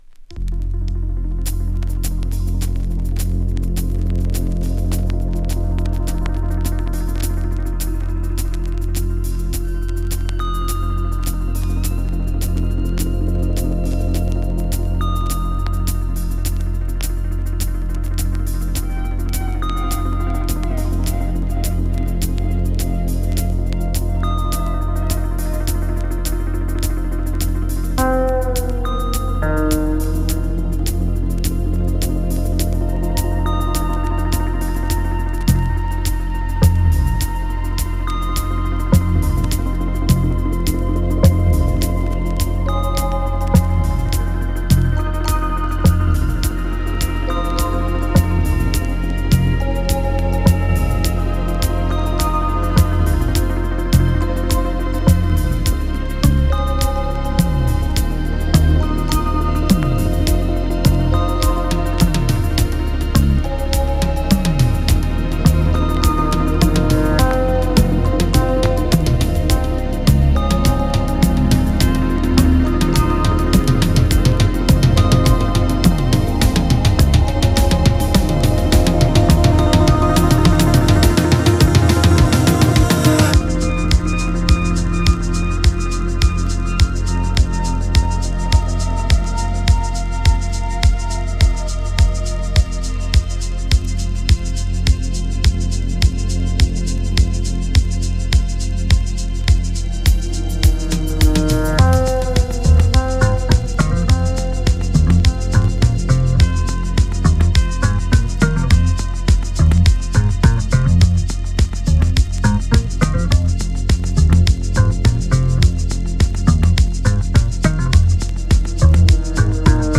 2. > TECHNO/HOUSE